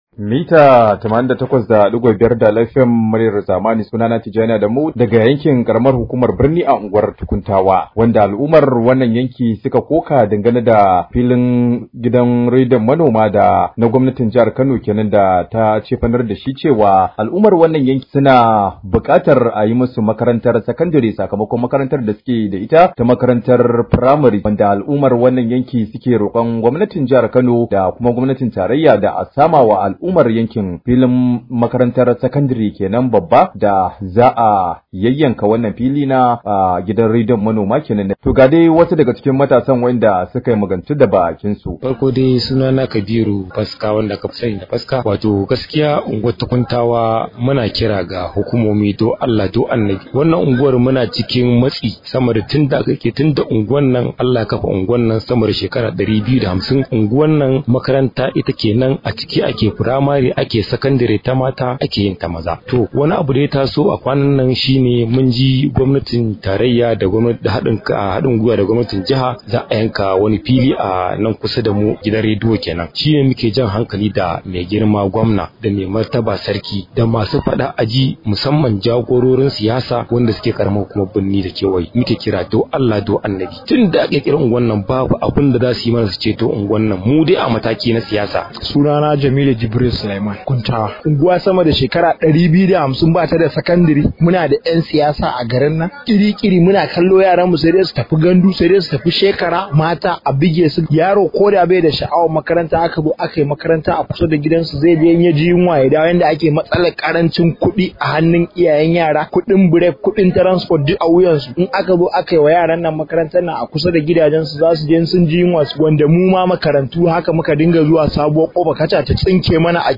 Domin cikakken rahon saurari wannan.